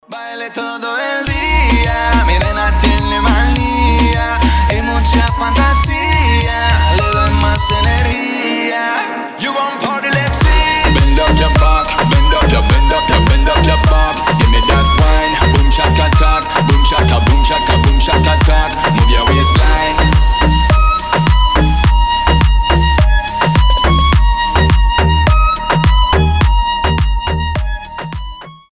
• Качество: 320, Stereo
ритмичные
Moombahton
испанские